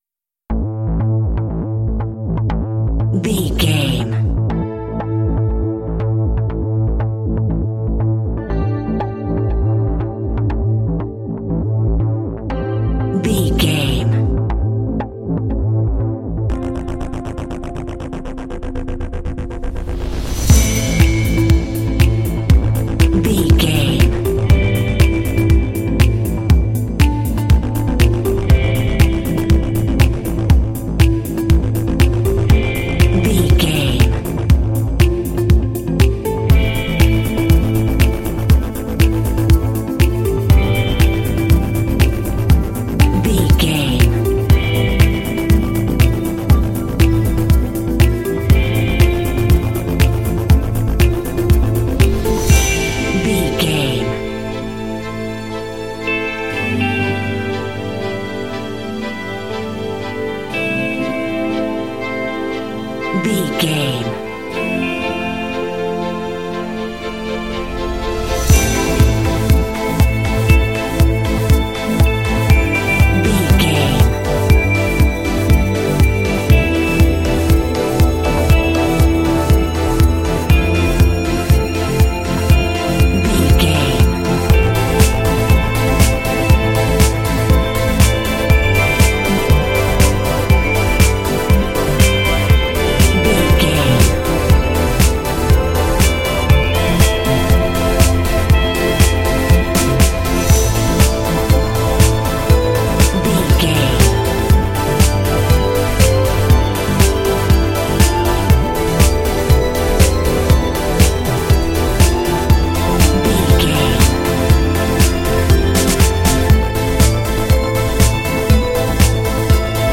Aeolian/Minor
bouncy
happy
uplifting
synthesiser
drums
electric guitar
strings
symphonic rock
synth- pop